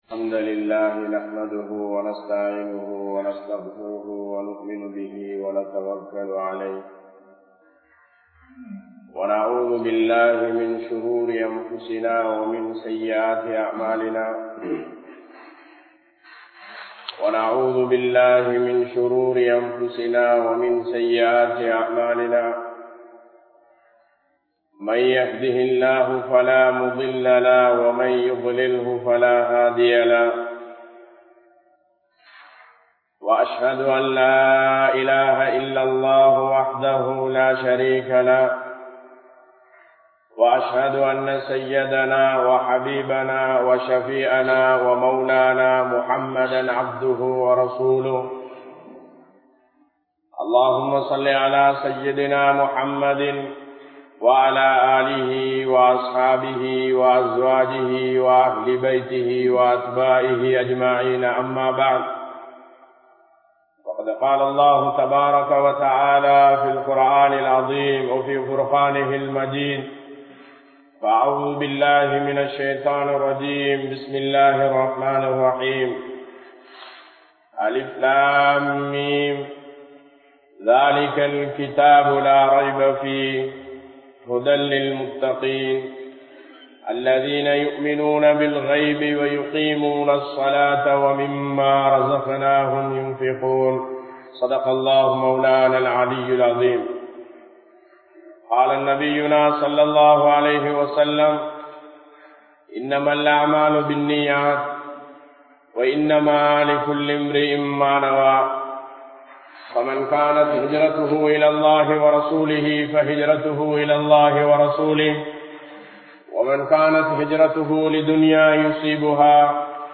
Nallavaihalukku Selavu Seiungal (நல்லவைகளுக்கு செலவு செய்யுங்கள்) | Audio Bayans | All Ceylon Muslim Youth Community | Addalaichenai